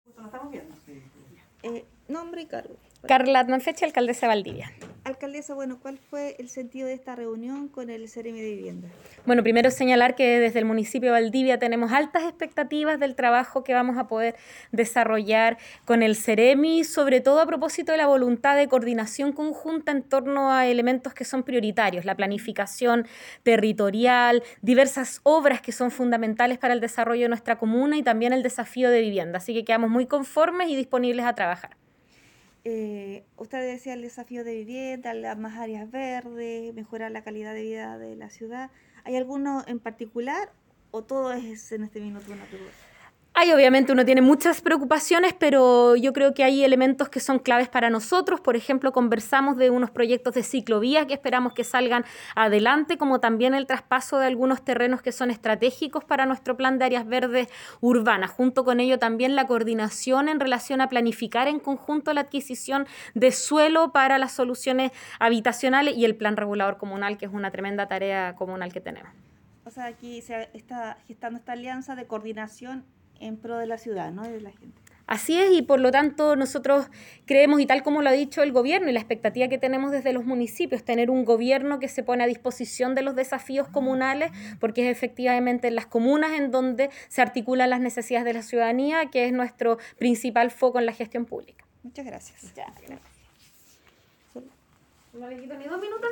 cuña alcaldesa Valdivia
cuna-alcaldesa-Valdivia.aac